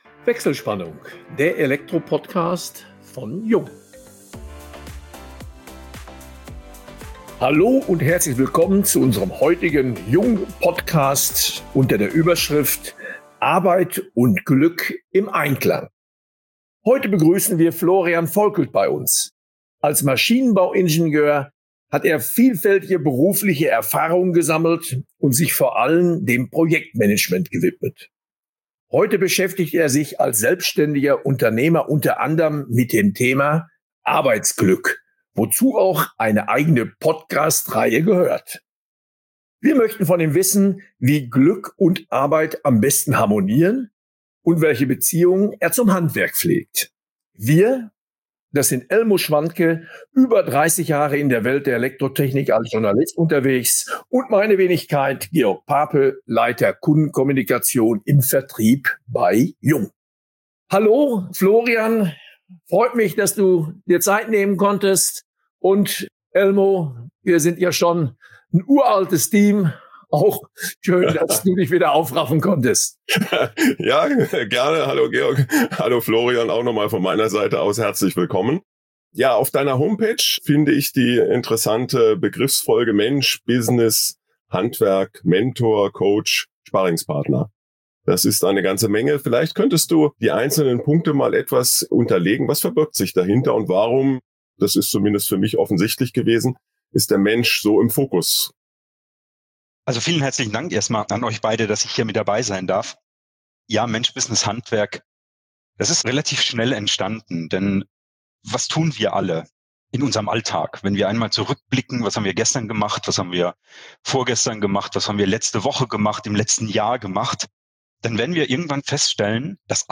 In vertrauensvollen Gesprächen diskutieren die Moderatoren